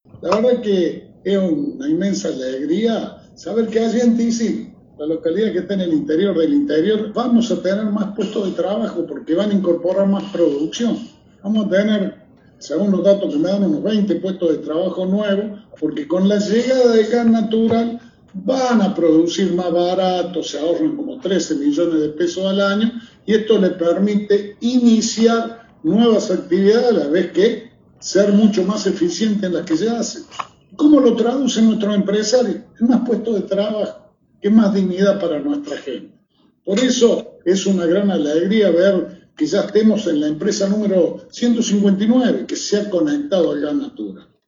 Audio: Gobernador Juan Schiaretti.